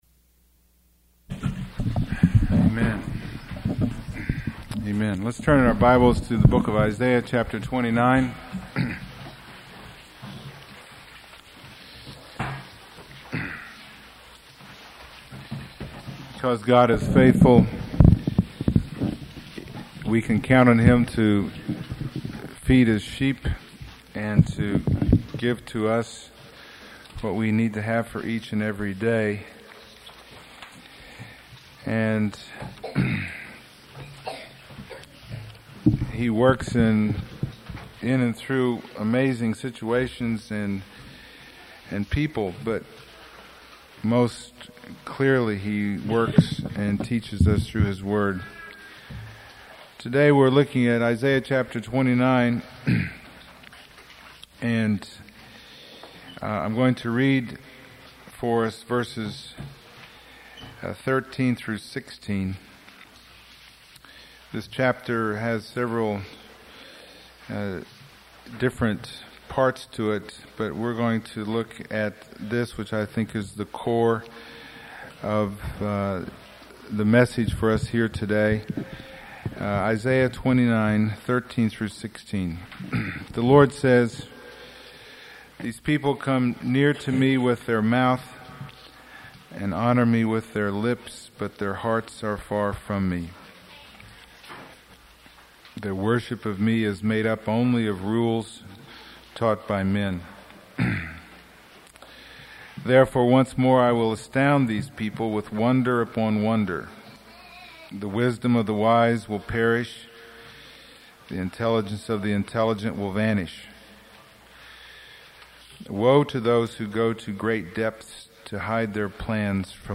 Isaiah Passage: Isaiah 29:13-16 Service Type: Sunday Morning %todo_render% « There is Peace with God